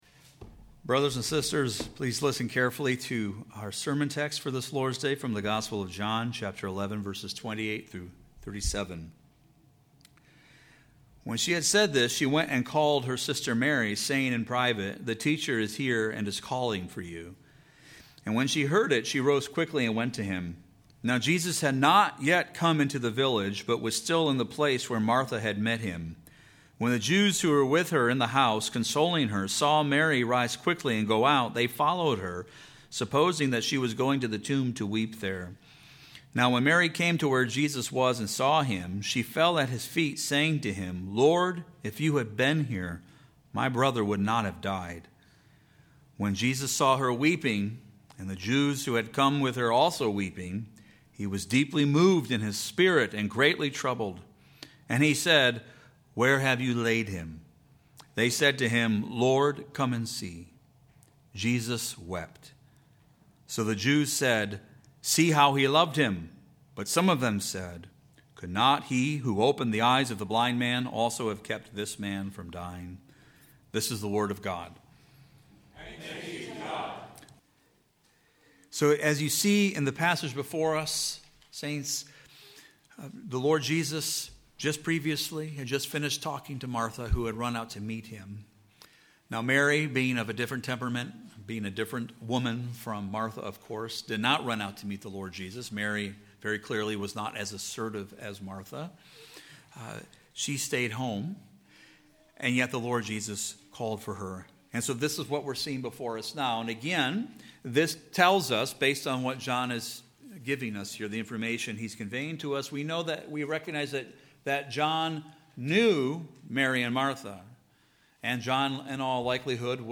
TRC_Sermon-2.1.26.mp3